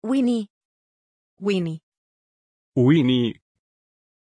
Pronunciation of Winnie
pronunciation-winnie-es.mp3